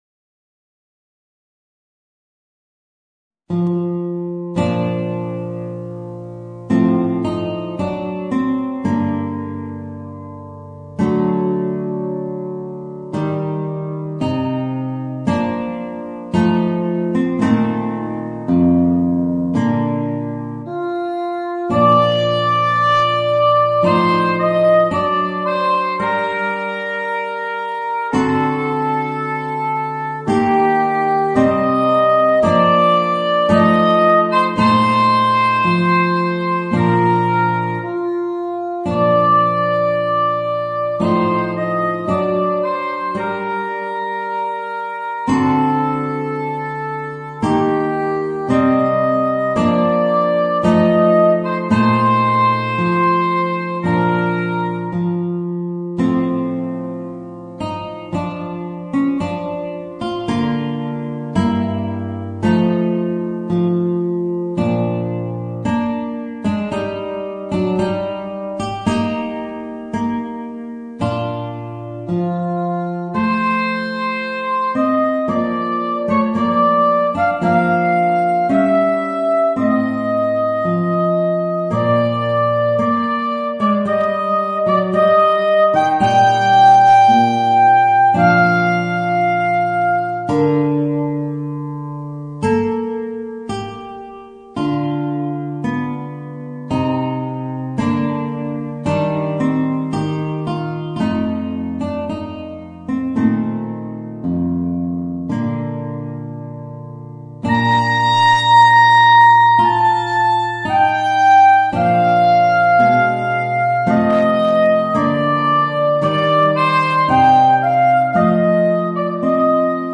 Voicing: Guitar and Soprano Saxophone